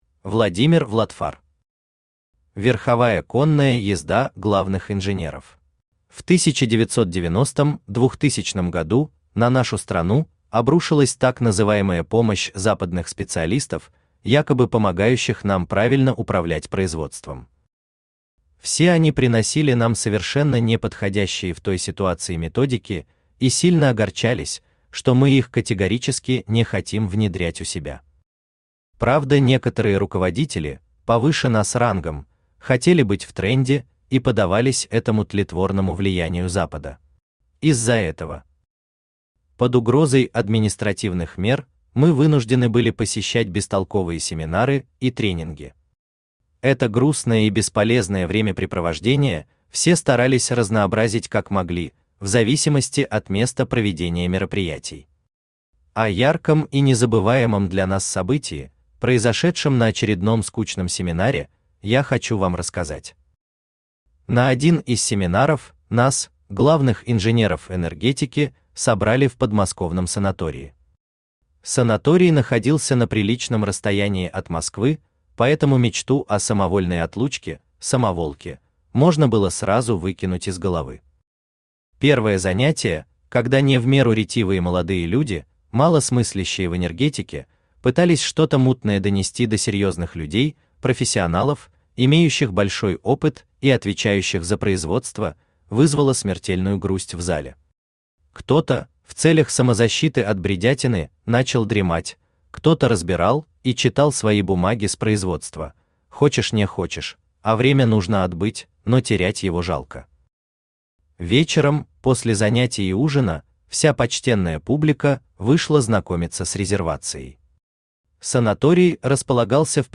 Aудиокнига Верховая конная езда главных инженеров Автор Владимир Vladfar Читает аудиокнигу Авточтец ЛитРес.